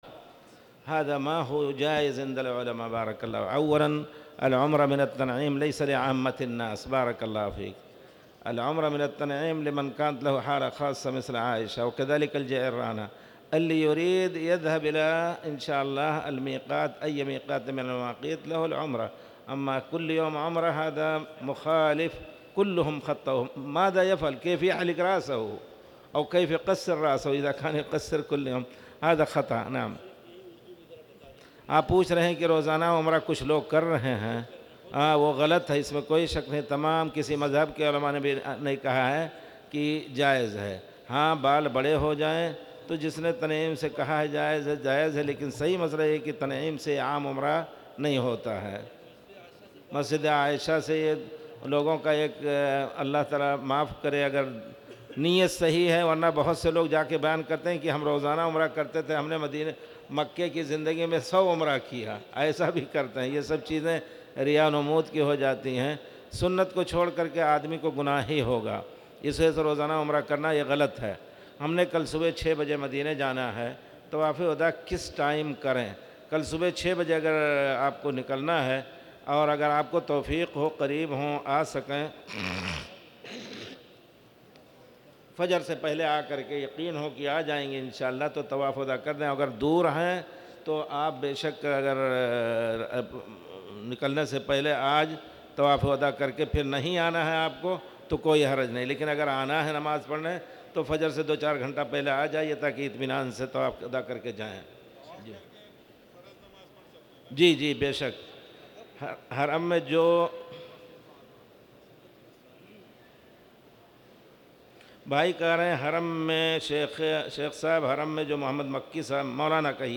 تاريخ النشر ٢٢ ذو الحجة ١٤٣٨ هـ المكان: المسجد الحرام الشيخ